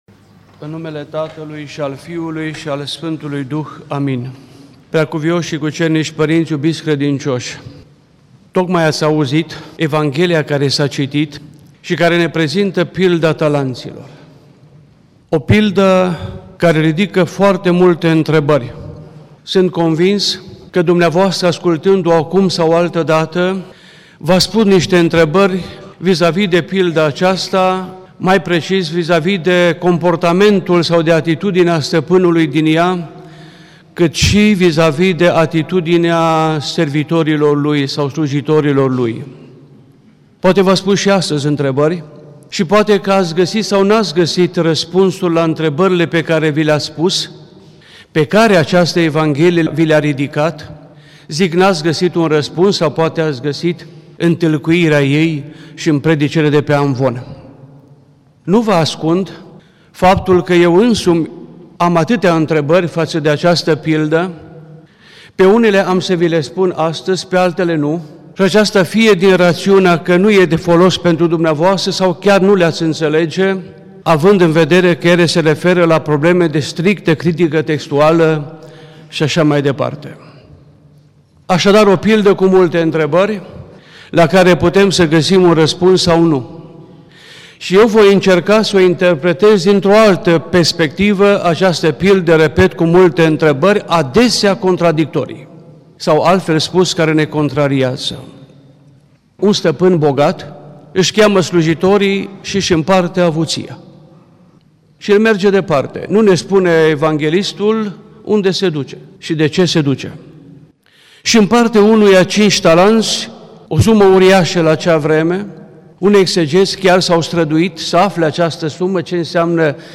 Cuvinte de învățătură Predică la Duminica a 16-a după Rusalii